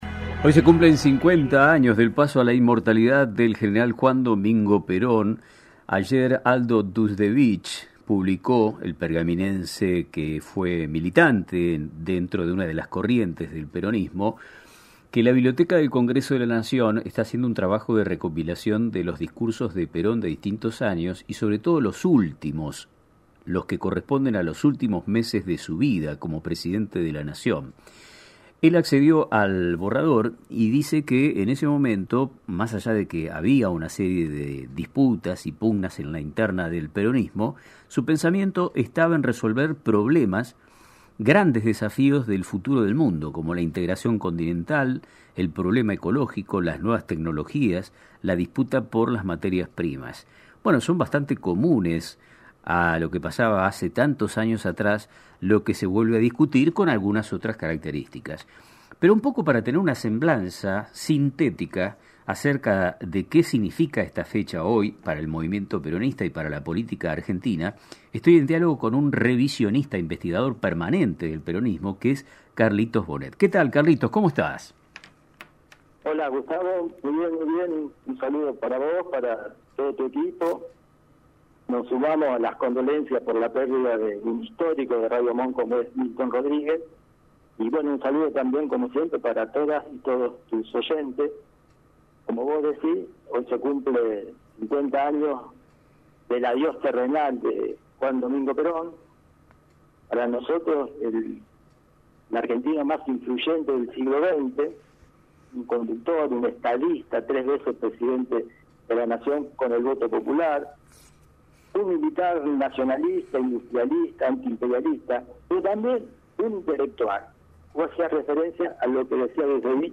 A 50 Años del Fallecimiento del General Juan Domingo Perón: Entrevista